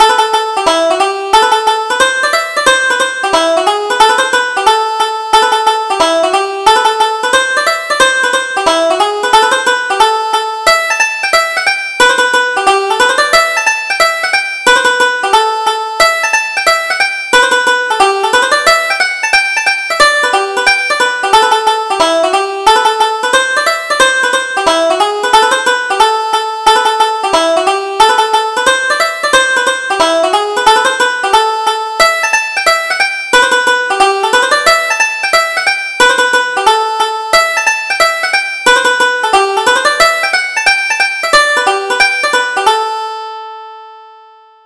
Reel: Follow Me Down to Carlow - 2nd Setting